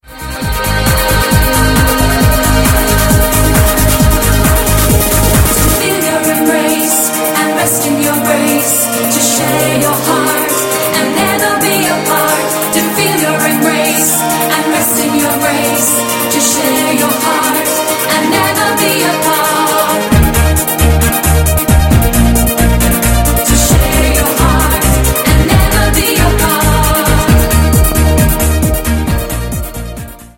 A Spititual Mix of Dance, Trance, Stadium and Club
• Sachgebiet: Dance